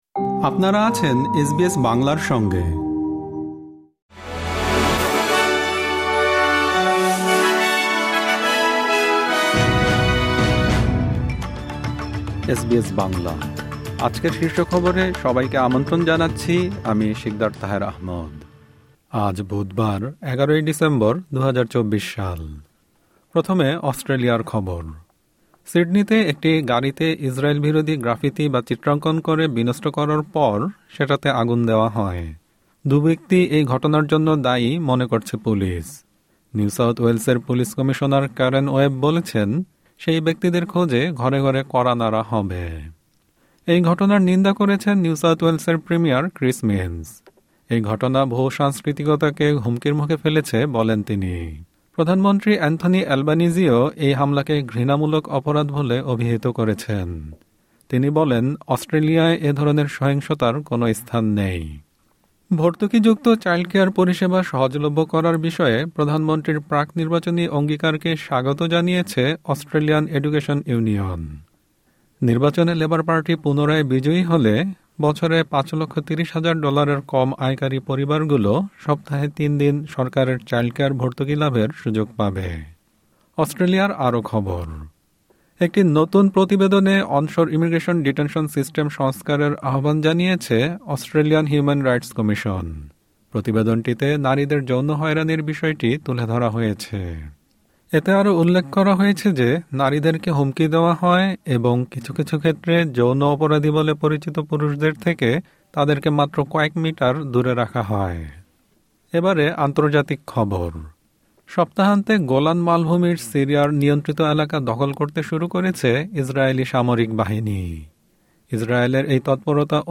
এসবিএস বাংলা শীর্ষ খবর: ১১ ডিসেম্বর, ২০২৪।